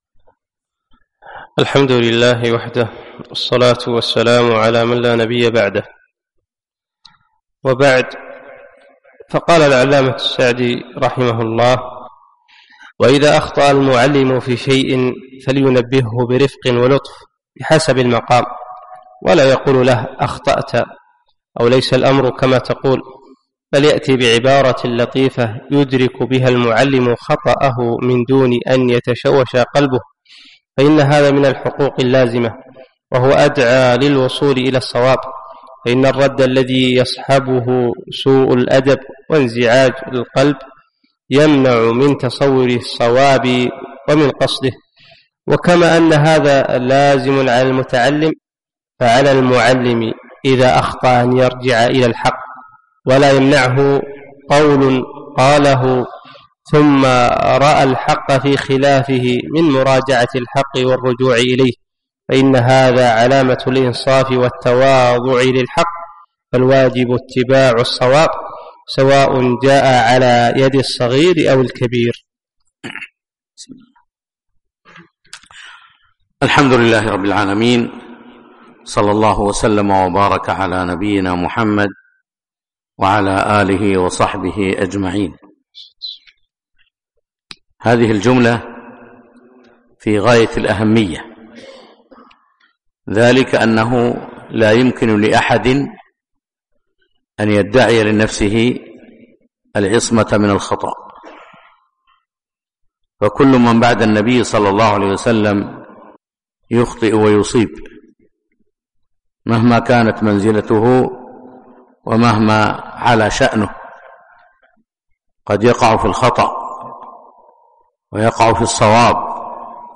فجر الثلاثاء 4 4 2017 مسجد صالح الكندري صباح السالم